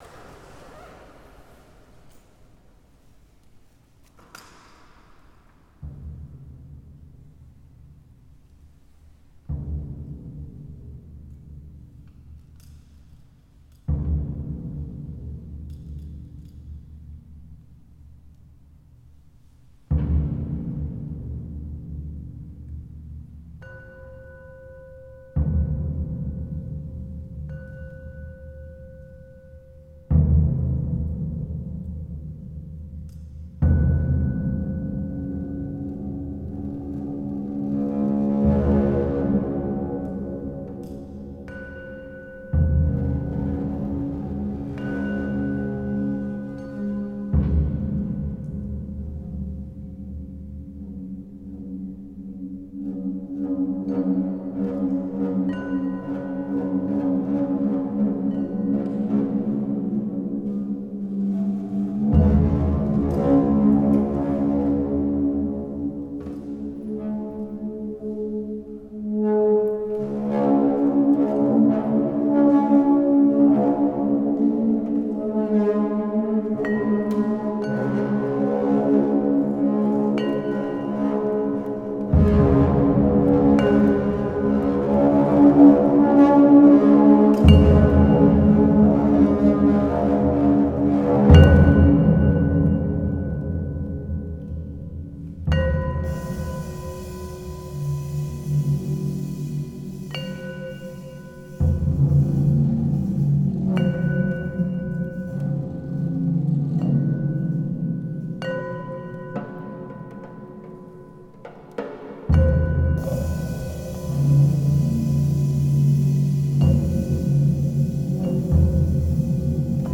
Solo Performance